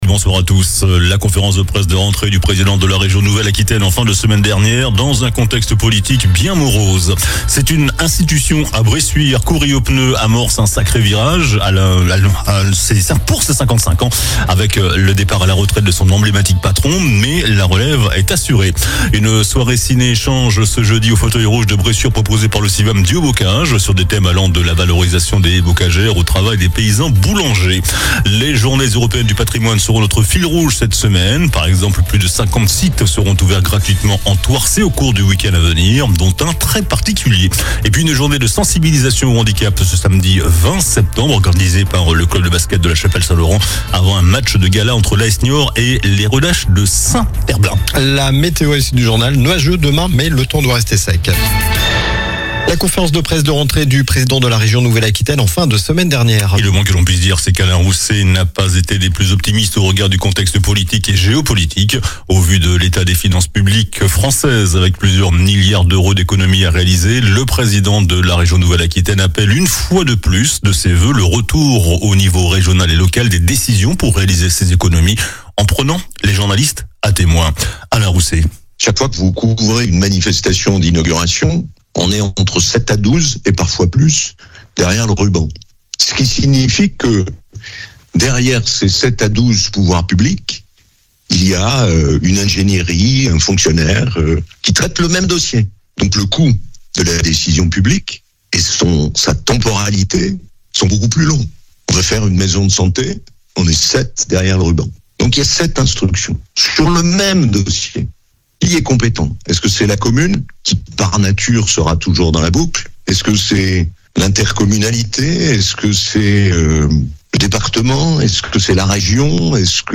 Journal du lundi 15 septembre (soir)
infos locales